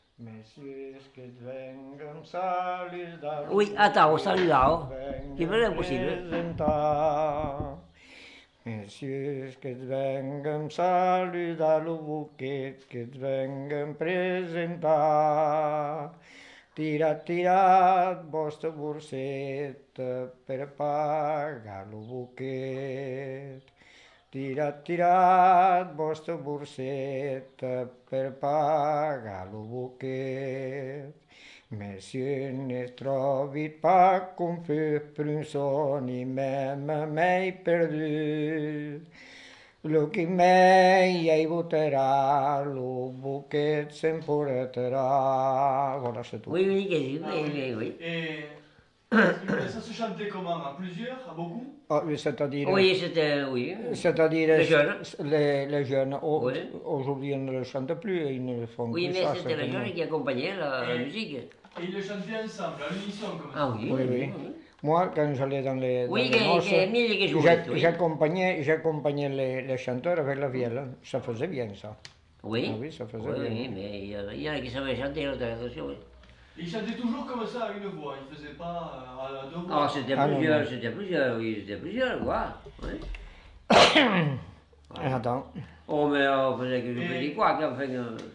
Lieu : Vielle-Soubiran
Genre : chant
Effectif : 1
Type de voix : voix d'homme
Production du son : chanté
Notes consultables : En fin de séquence, des commentaires sur la coutume du bouquet.